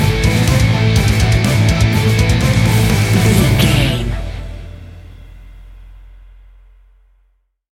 Epic / Action
Fast paced
Aeolian/Minor
Fast
hard rock
instrumentals
Heavy Metal Guitars
Metal Drums
Heavy Bass Guitars